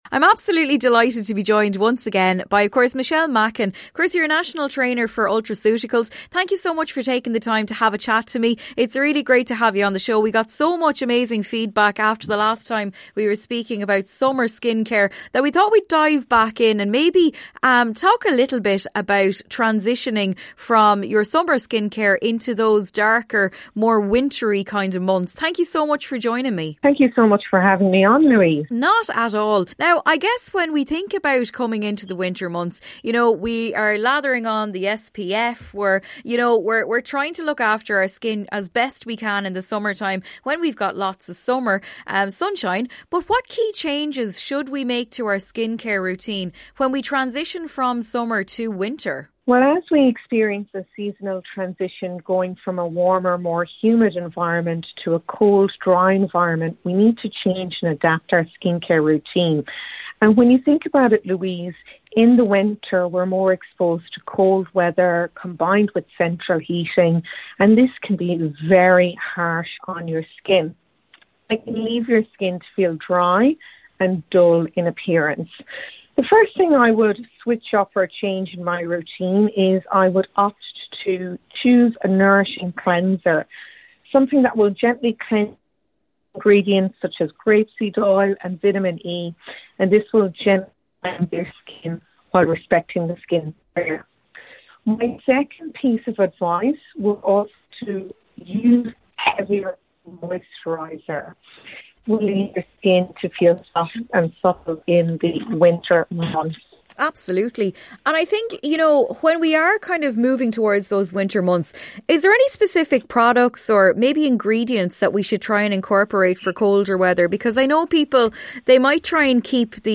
Cian Ducrot & Ella Henderson Interview - 19.12.2023